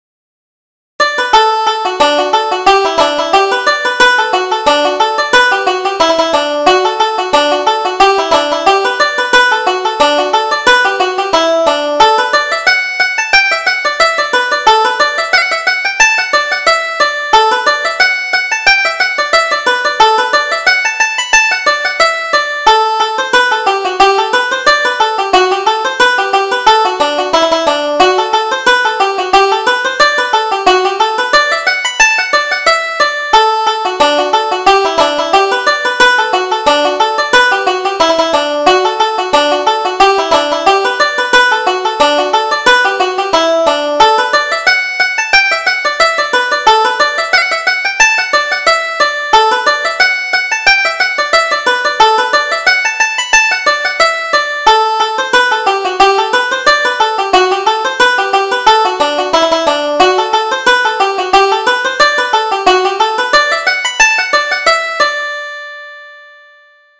Reel: Rolling on the Ryegrass